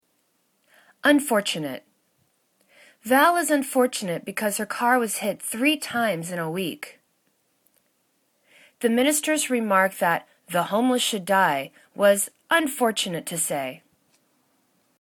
un.for.tu.nate  /un'faw:rchәnәt/ adj